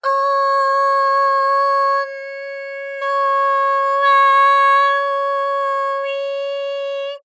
ALYS-DB-001-JPN - First Japanese UTAU vocal library of ALYS.
o_o_n_o_a_o_i.wav